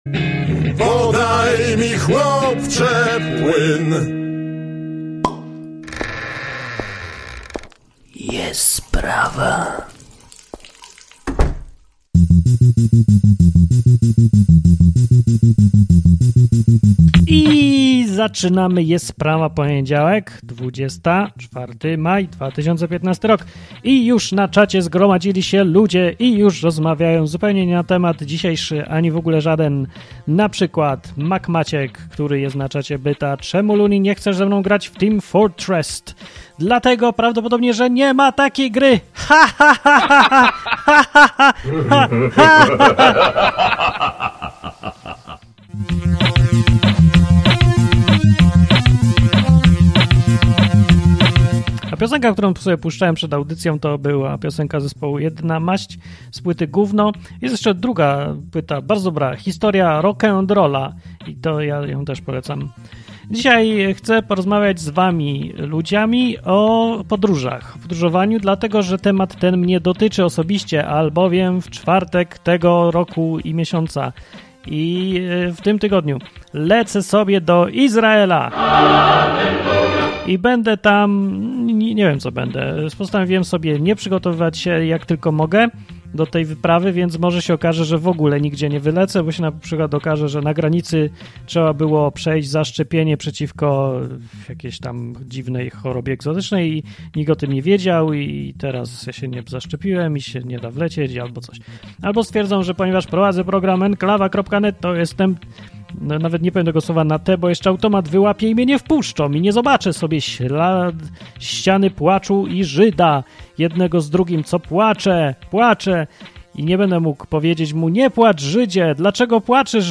Taką stawiam tezę, a słuchacze dzwonią i mówią inaczej. Usłyszysz o Belgii, Paryżu, Ukrainie, Hiszpanii, USA i innych miejscach.